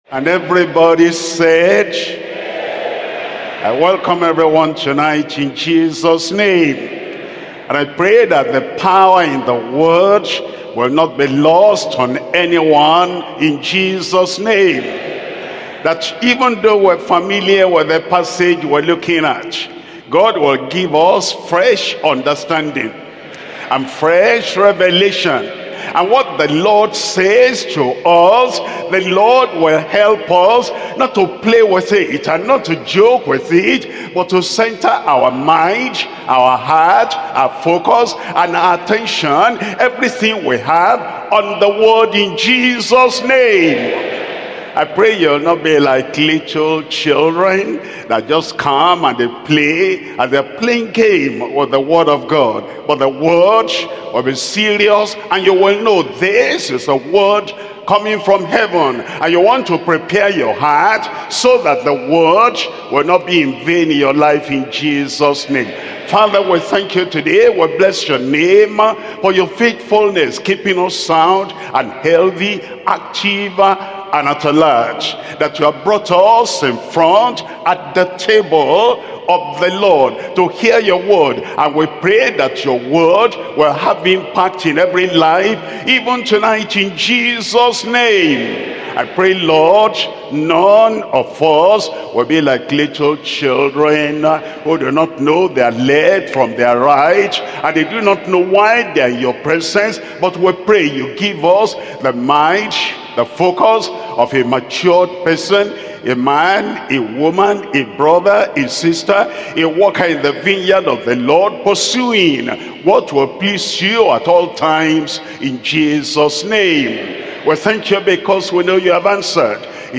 Sermons – Deeper Life Bible Church Dubai, UAE